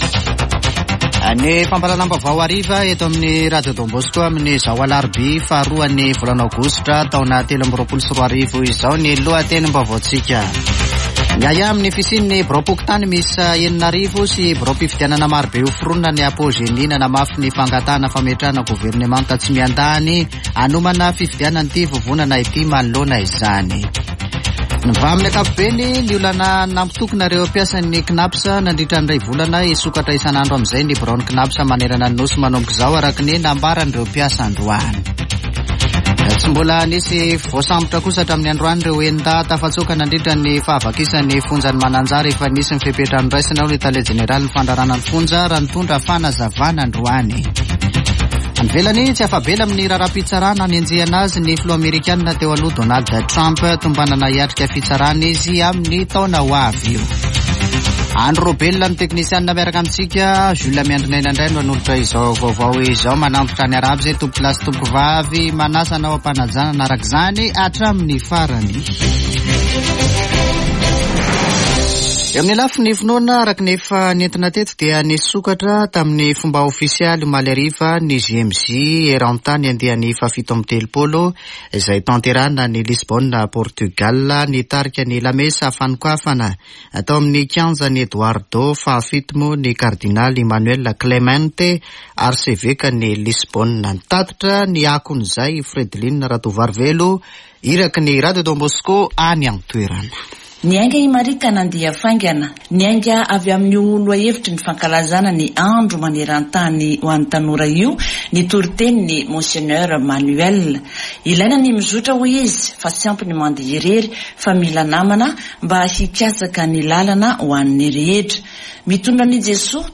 [Vaovao hariva] Alarobia 2 aogositra 2023